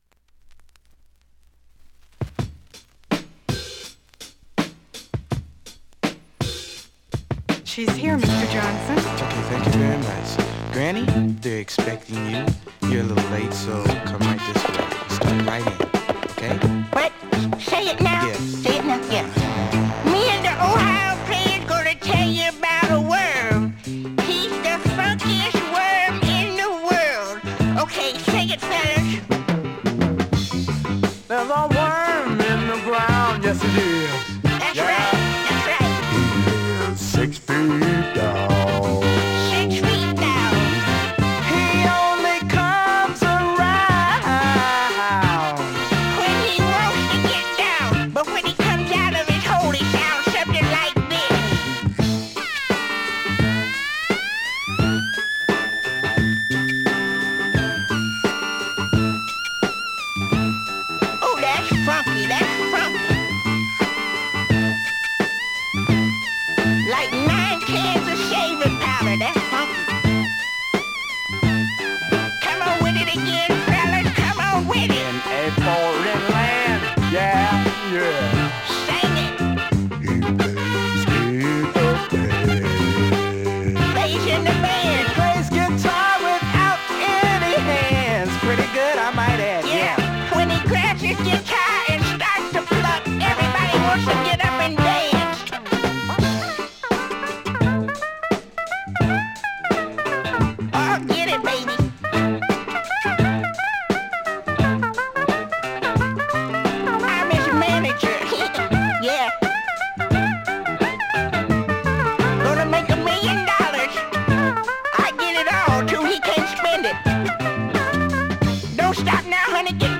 ◆盤質両面/VG+◆音質は良好です。